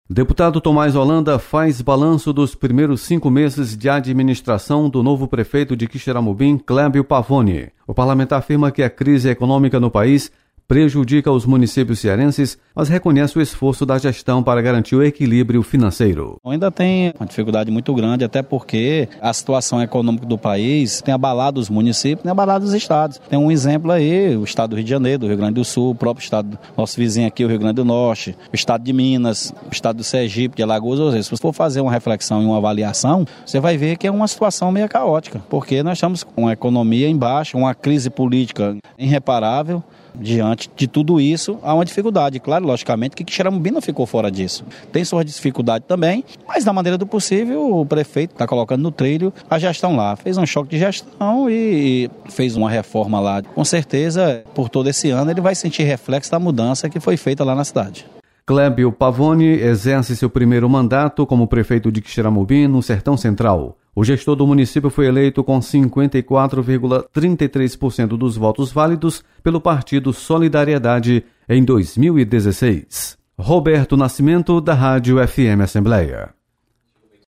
Plenário
Deputado Tomás Holanda faz balanço dos cinco meses da nova administração de Quixeramobim. (1'17'').